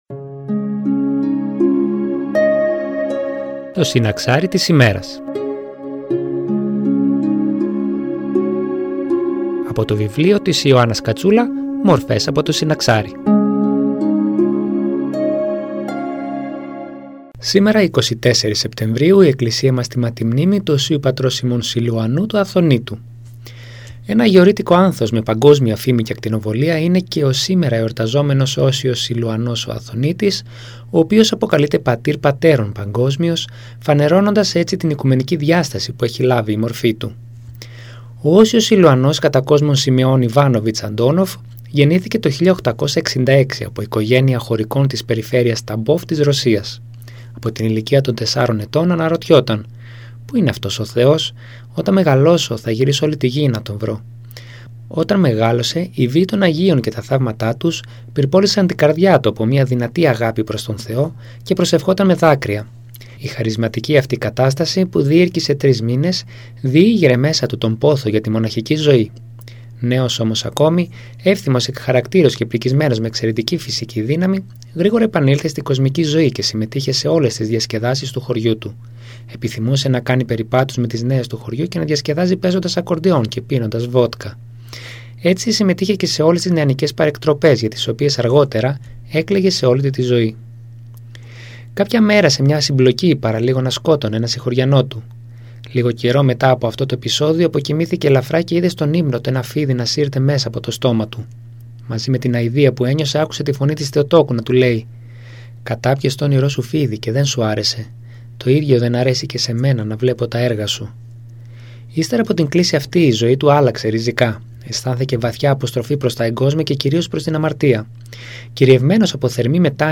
Εκκλησιαστική εκπομπή